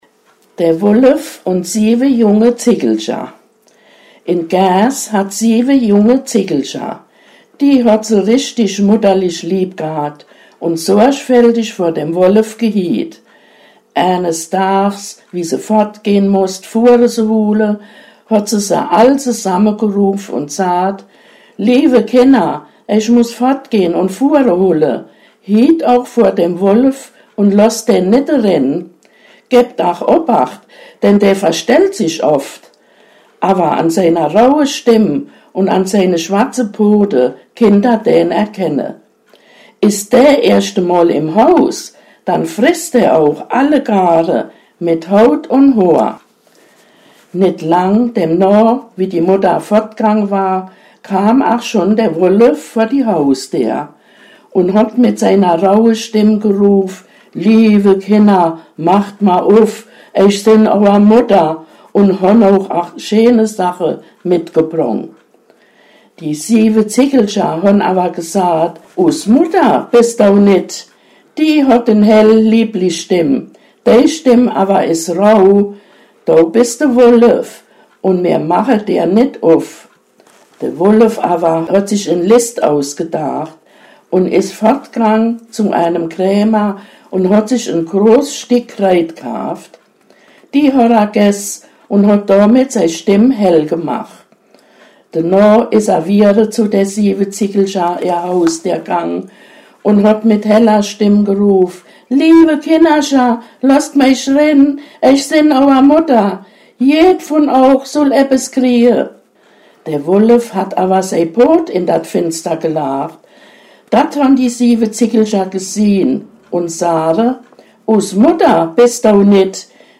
Plattdeutsch und Hochdeutsch Sohrener Aussprache Wäst de noch? Uff dä Bleich Wochenplan Wochentage Zahlen De Wolf unn die 7 Zickel Die drei Spinnerinne Die golden Gans